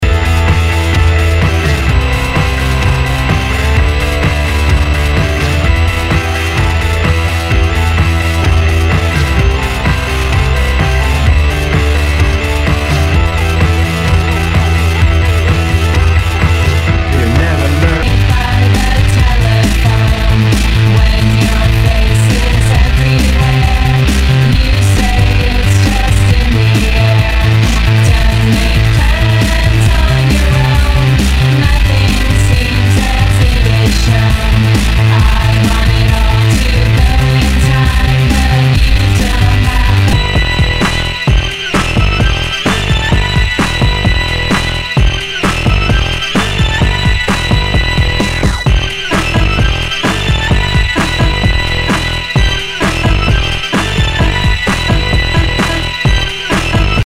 HOUSE/TECHNO/ELECTRO
ナイス！エレクトロ！